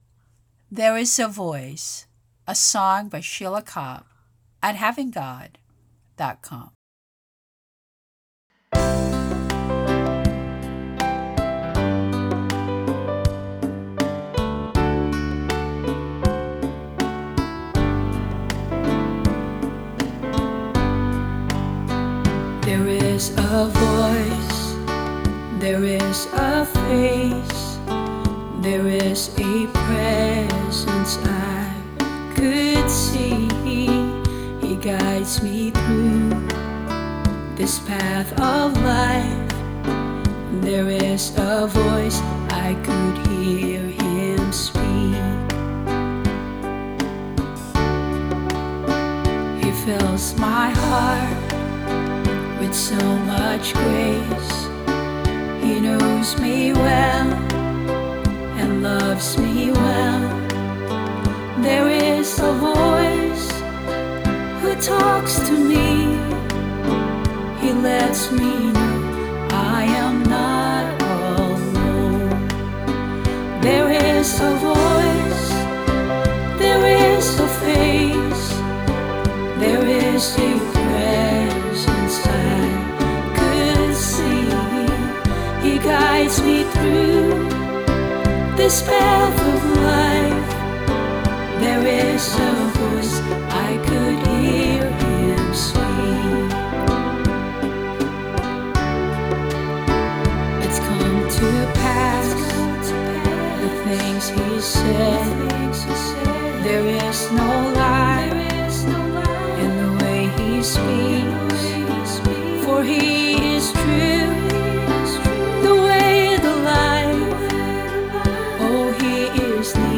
Vocals
Piano and strings and other piano sounds
Other music produced through Band-In-A-Box